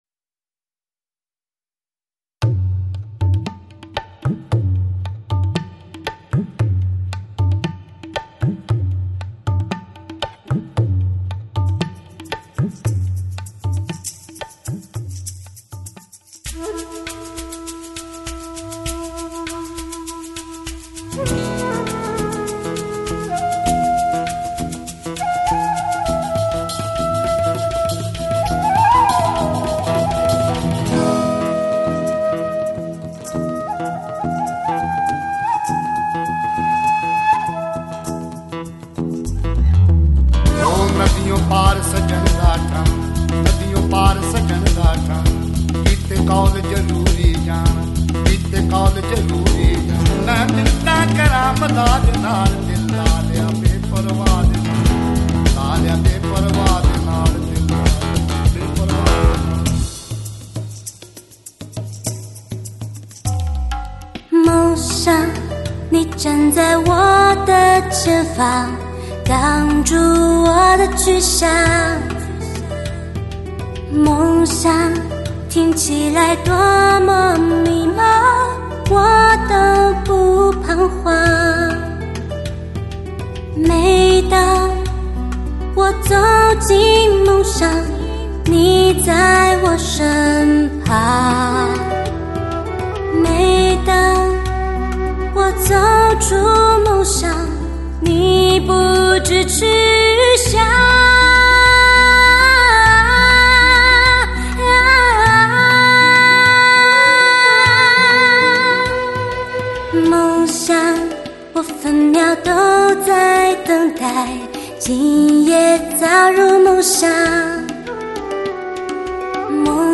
大量闻所未闻的民族特色打击乐和各类地方民乐，又加入中国民乐的演奏，在整个动态/声场/